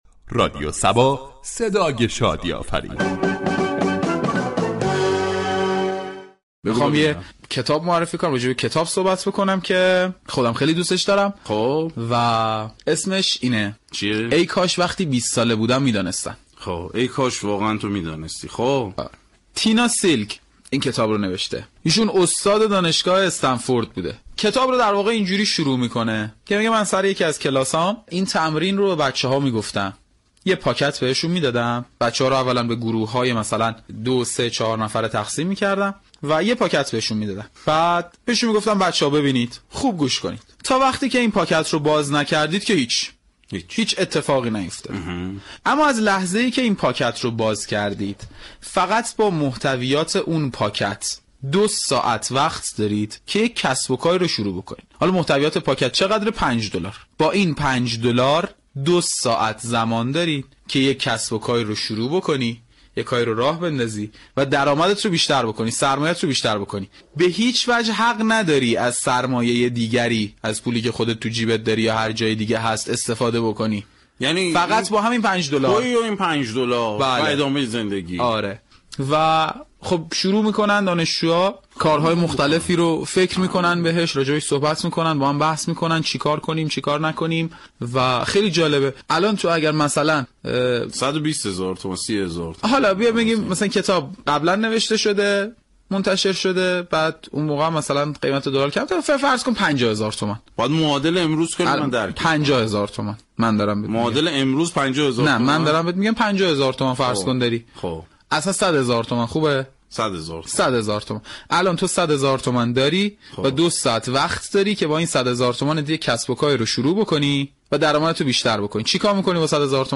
رادیو صبا در برنامه صدویك راه برای كتاب نخواندن این كتاب را به مخاطبان معرفی می كند ، در این برنامه با خوانش بخش های از كتاب و كارشناسی در مورد آن این كتاب به مخاطبان پیشنهاد میشود.